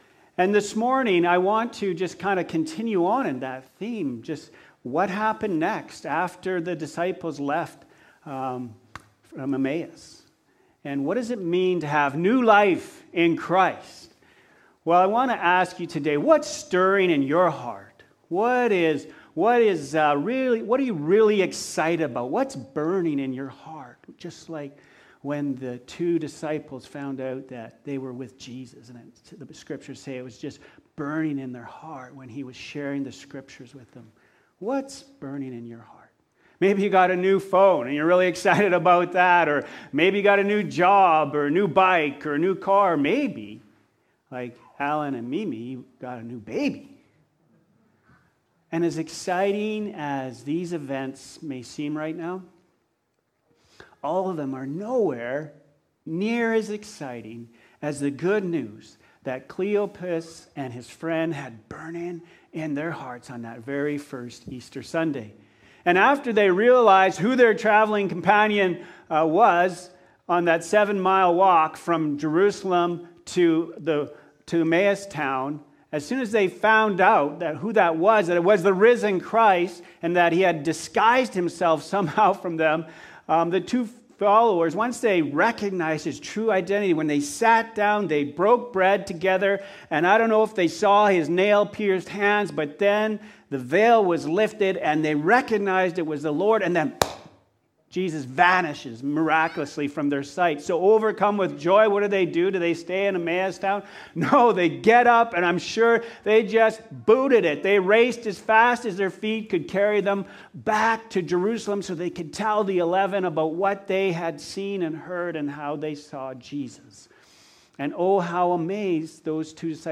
April-4-2021-Easter-Sunday-Service.mp3